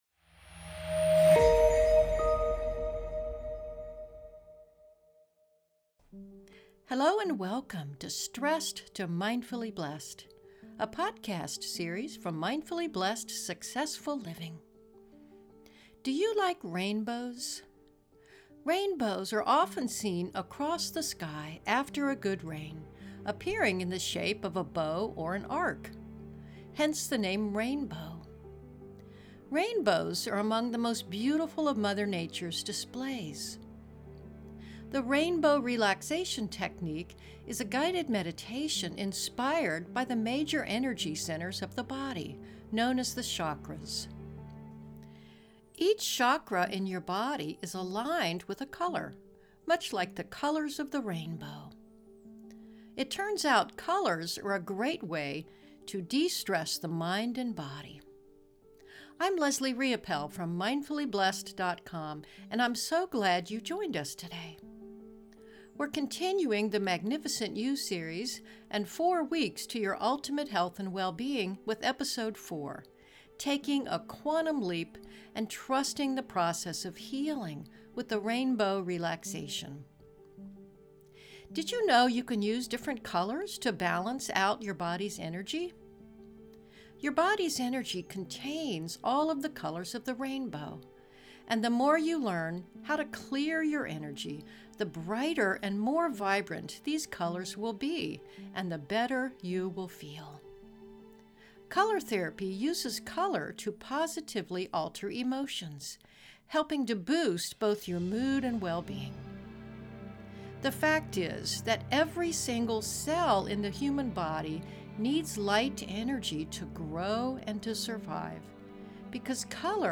Rainbow Relaxation Meditation